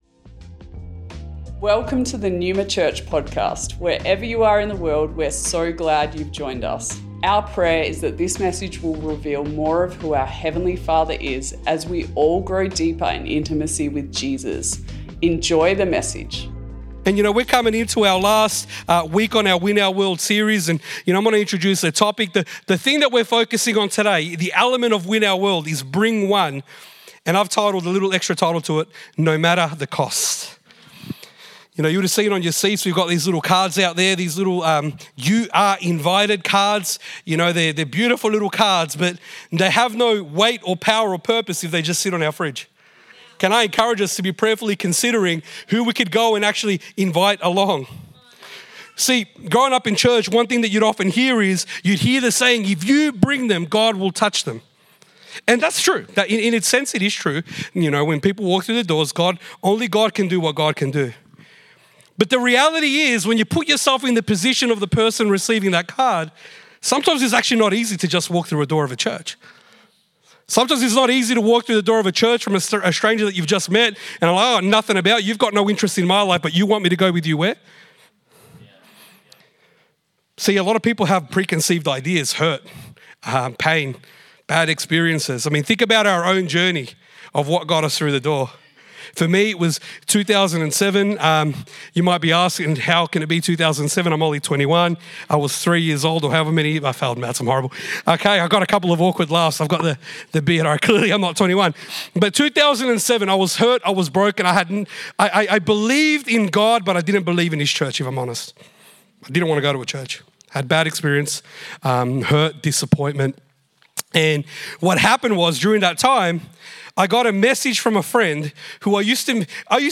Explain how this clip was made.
Originally recorded at Neuma Melbourne West August 11th 2024